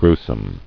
[grue·some]